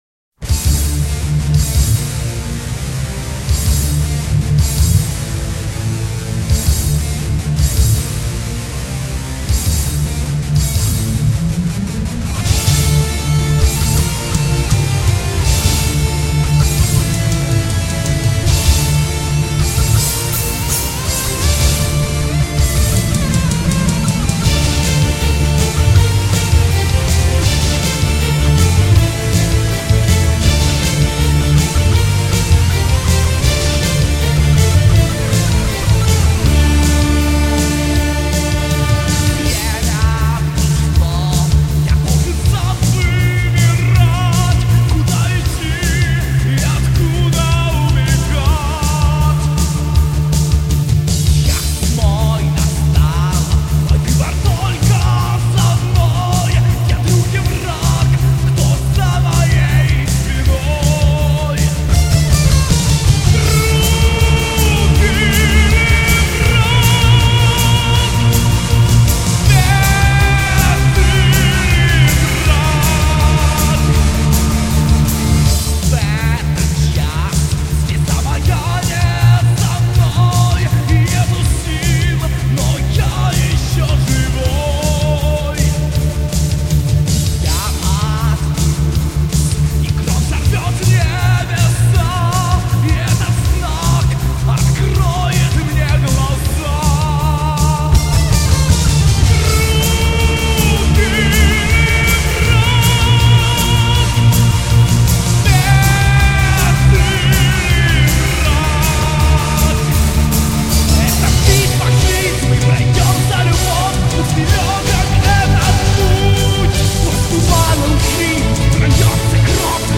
стиль у группы melodic и power metal.
Вокал, клавиши
ударные
бас гитара